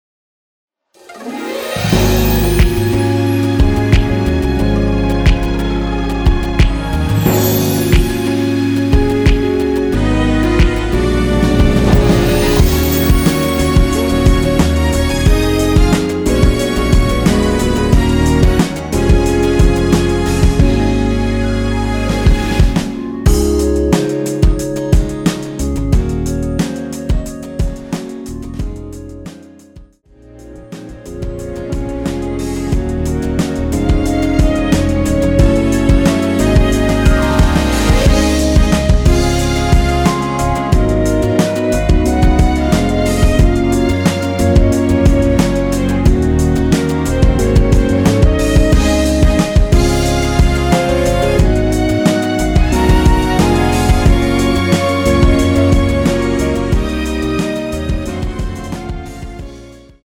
원키에서(-2)내린 (1절앞+후렴)으로 진행되는 멜로디 포함된 MR입니다.(미리듣기 확인)
Db
앨범 | O.S.T
앞부분30초, 뒷부분30초씩 편집해서 올려 드리고 있습니다.
중간에 음이 끈어지고 다시 나오는 이유는